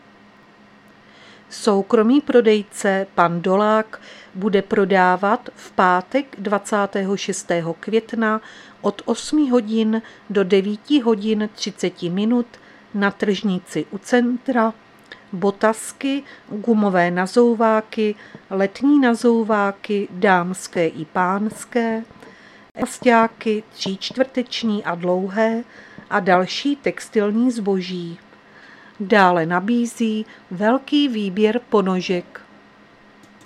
Záznam hlášení místního rozhlasu 25.5.2023